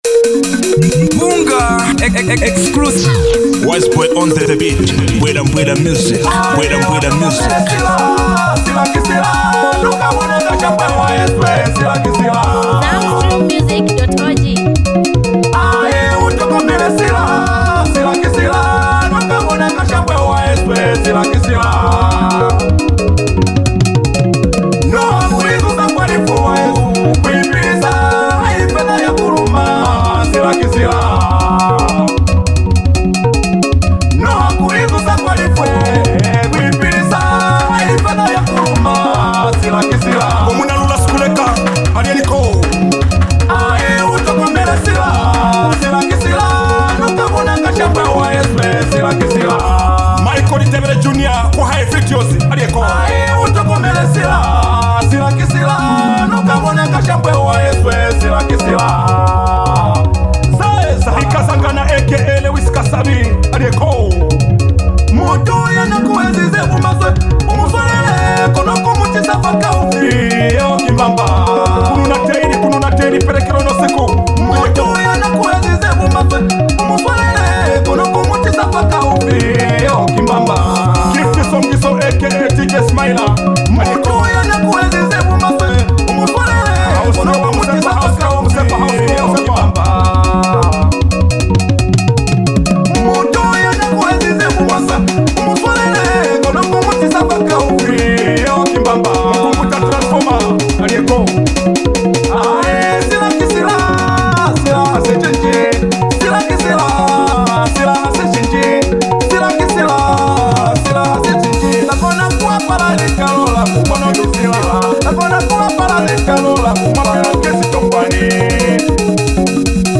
Home » Hottest » Music » Trending » Zambian Songs